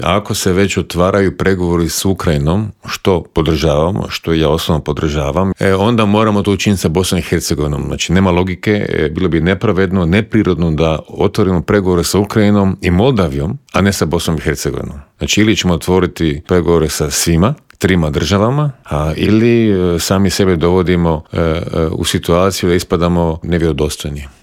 ZAGREB - Dok napetosti oko širenja Europske unije i nastavka pomoći Ukrajini traju, mađarski premijer Viktor Orban riskira i pokušava svojoj državi priskrbiti sredstva koja je Europska unija zamrznula, pojašnjava u Intervjuu Media servisa bivši inoministar Miro Kovač.